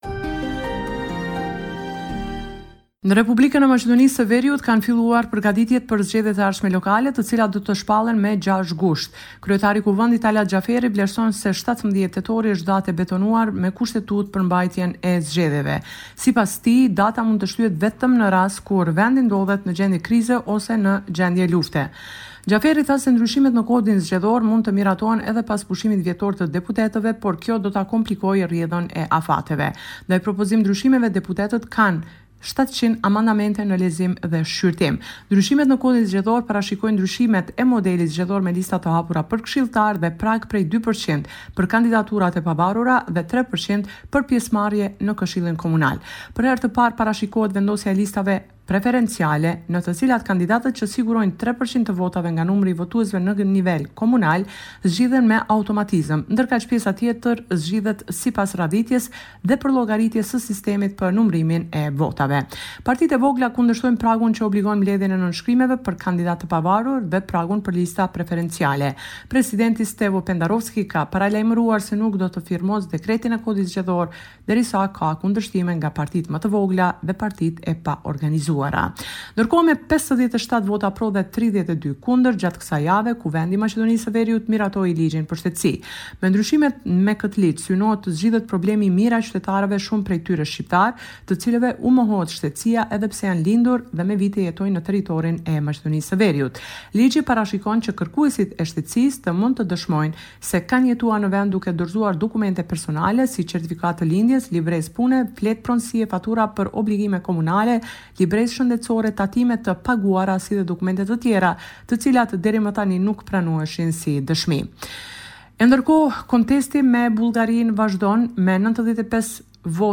This is a report summarising the latest developments in news and current affairs in North Macedonia.